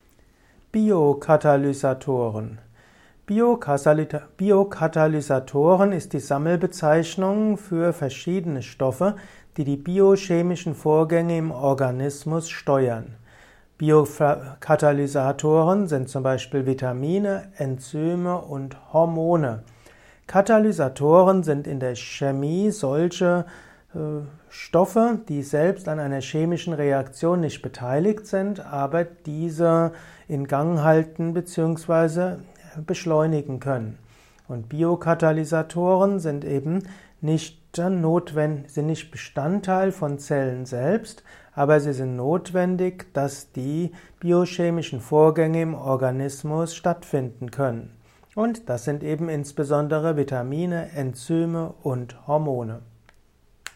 Kompakte Informationen zum Thema Biokatalysatoren in diesem Kurzvortrag